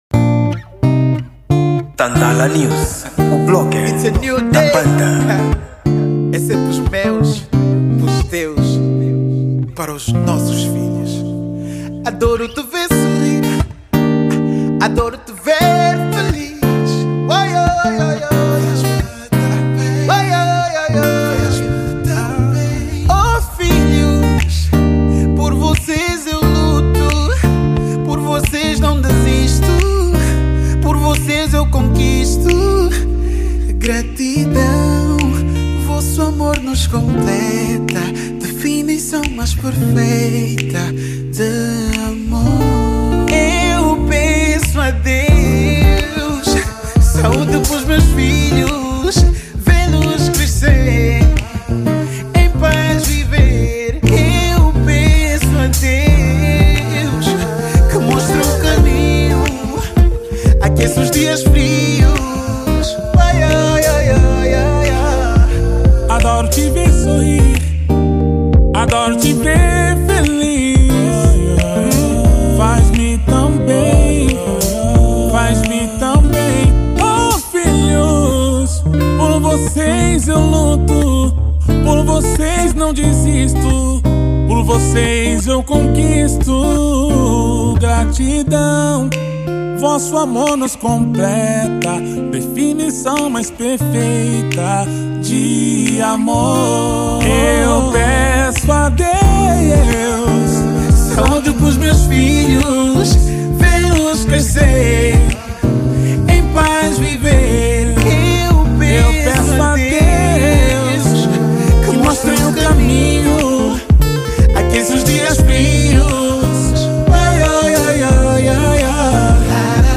Gênero: Rnb